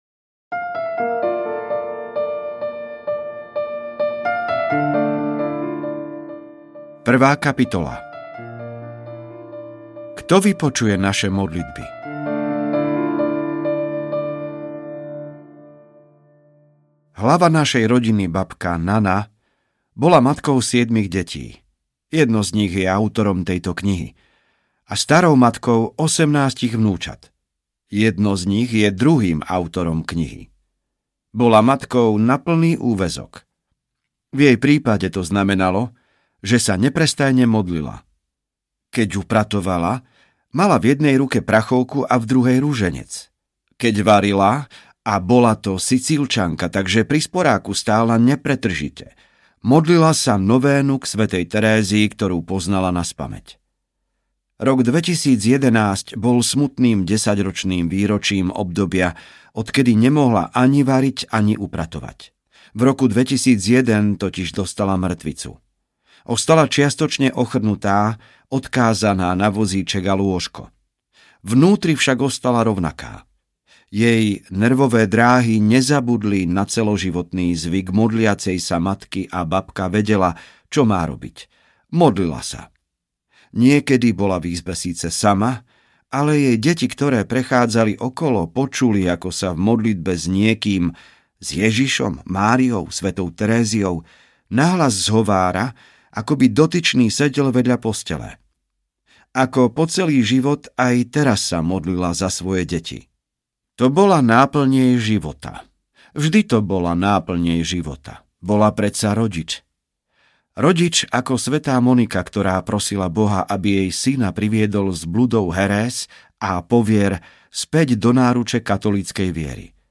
Svätá Monika: Moc vytrvalej modlitby audiokniha
Ukázka z knihy